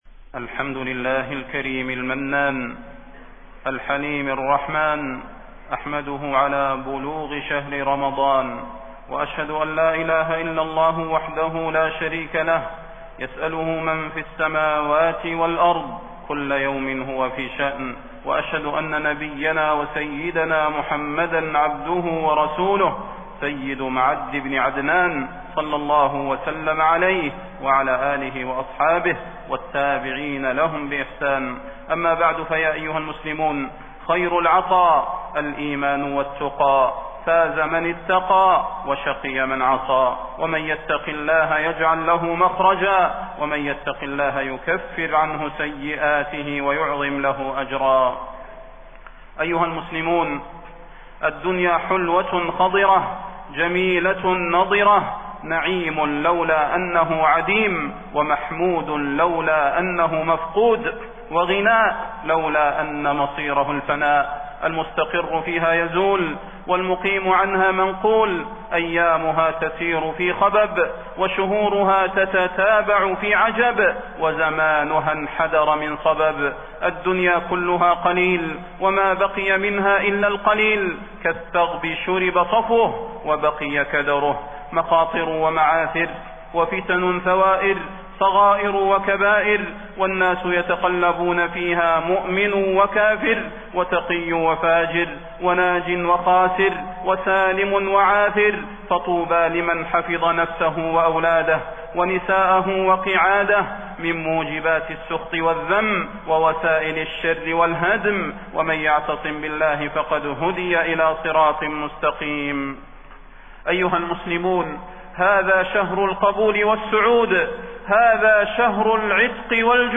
تاريخ النشر ١٠ رمضان ١٤٣١ هـ المكان: المسجد النبوي الشيخ: فضيلة الشيخ د. صلاح بن محمد البدير فضيلة الشيخ د. صلاح بن محمد البدير اغتنم رمضان بالتوبة قبل رحيله